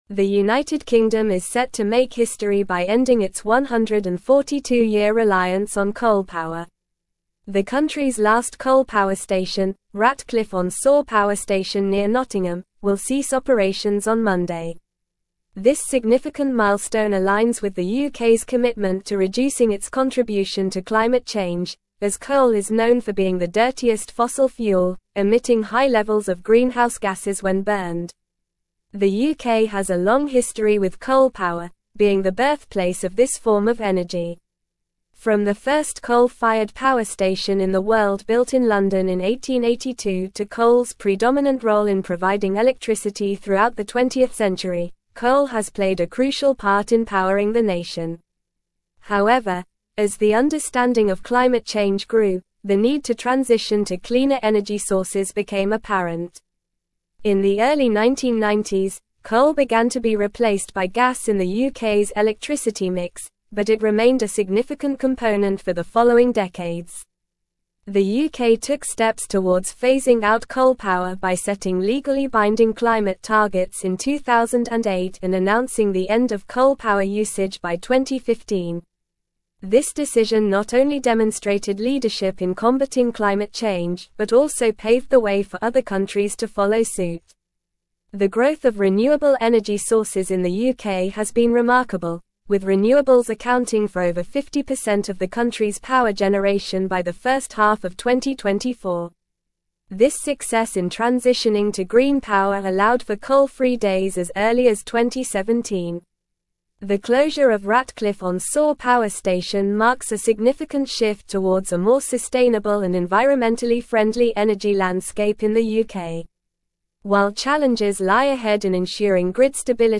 Normal
English-Newsroom-Advanced-NORMAL-Reading-UK-Ends-142-Year-Coal-Reliance-Shifts-to-Renewables.mp3